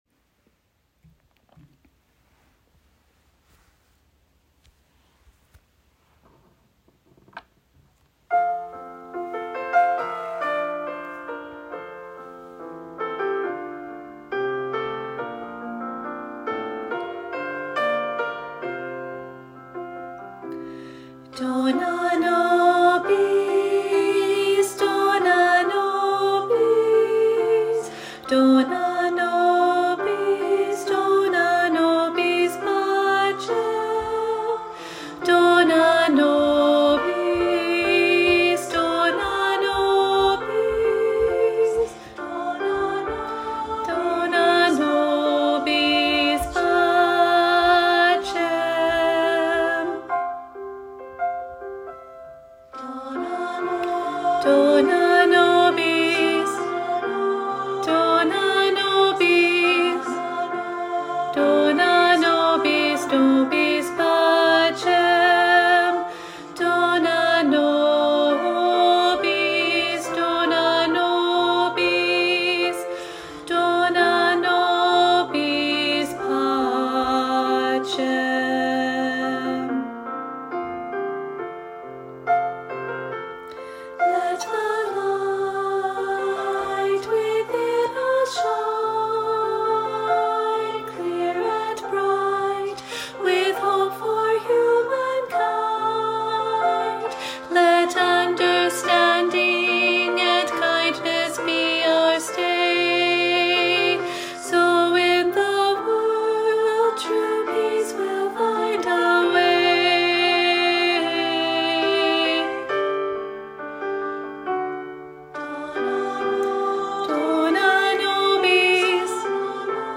Practice track for Intermediate Choir (part 2)